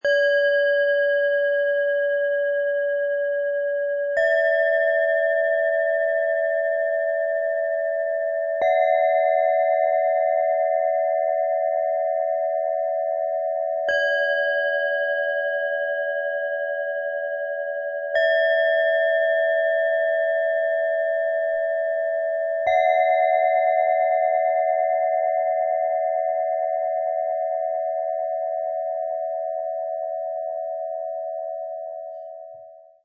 Engelslicht: Klang der Leichtigkeit - Set aus 3 Klangschalen, Ø 10,3 - 11,1 cm, 0,95 kg
Ein feines, zartes Klangschalen-Set mit hellen Tönen und lichtvoller Ausstrahlung.
Tiefster Ton – einladend, öffnend, sanft
Mittlerer Ton – herzerwärmend, freundlich, lichtvoll
Ihr Klang wirkt warm und strahlend.
Höchster Ton – leicht, fröhlich, erhebend
Das Ergebnis ist ein bewegender Klang – fein, hell und voller Schwingung.
MaterialBronze